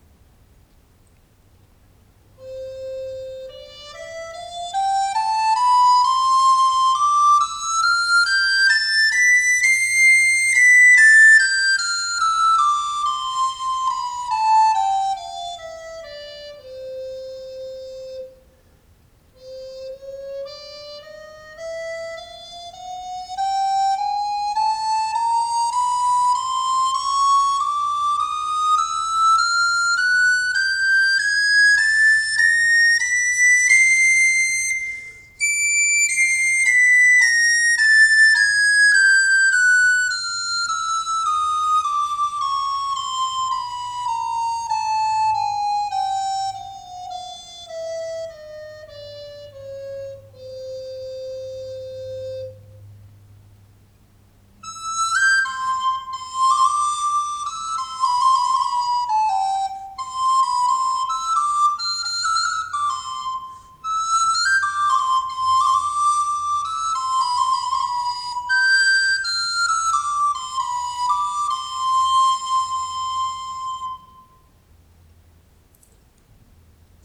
【材質】エボニー
材の比重が高く硬質な音色が魅力のエボニー（黒檀）、音のよく通るソプラノとの相性は抜群。類似した材にグレナディラがありますが、黒檀はそれほどシャープな印象はなく、タケヤマらしいヴォイシングもあってまろやかな音色も作れる楽器です。独奏向きではありますが、アンサンブルのトップにも向くでしょう。